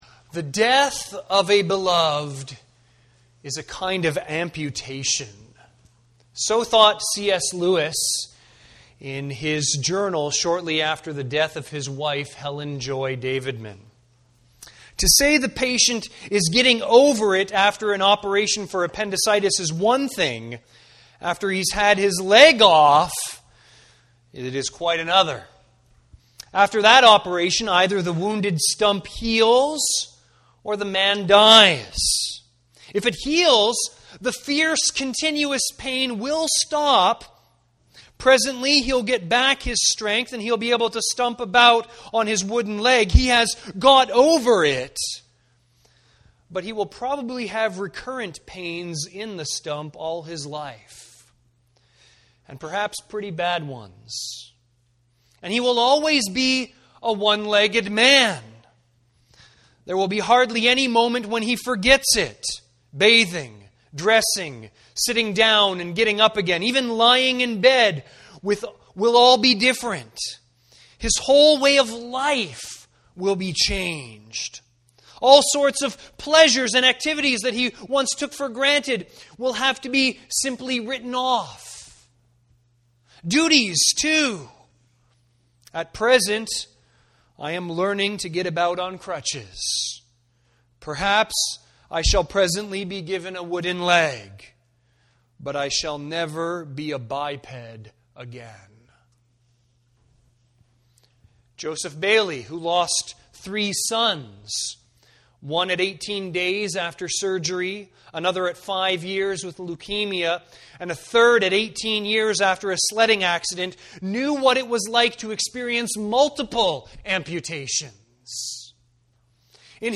Sermons | Campbell Baptist Church